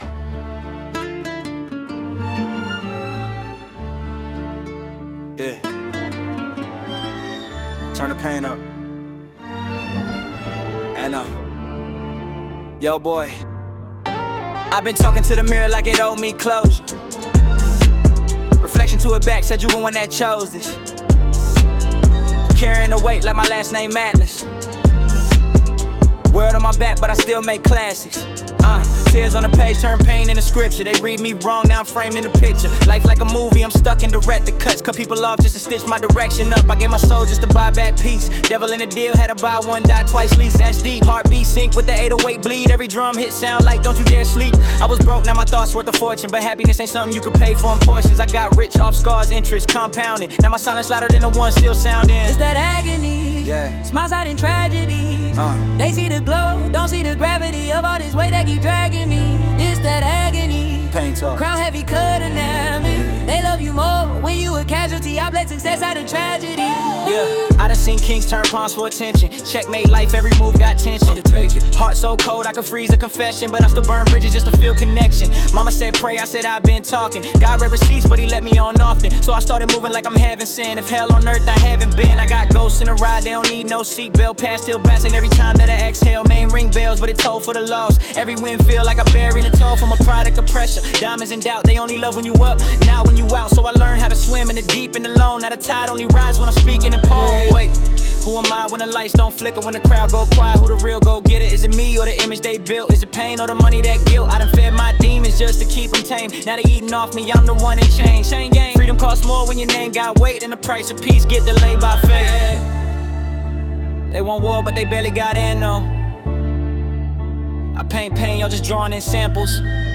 an epic cinematic journey